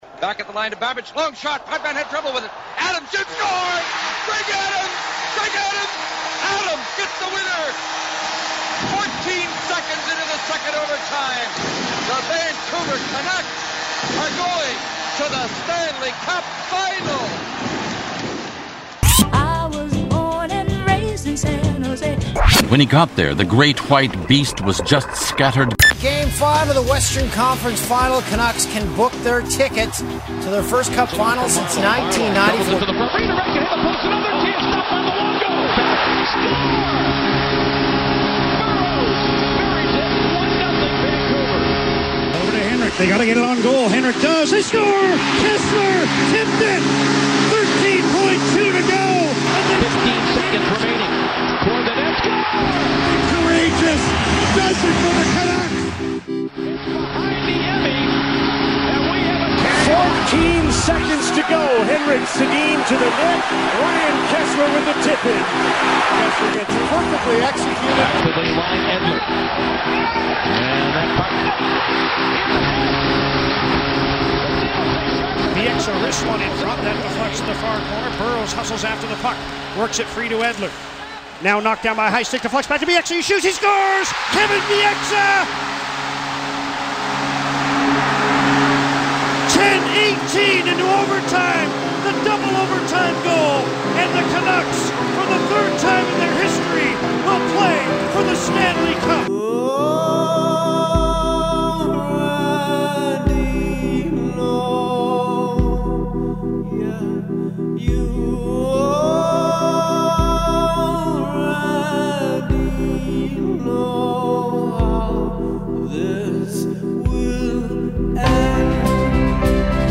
To celebrate I jammed out a little Canucks audio montage to relive the magic.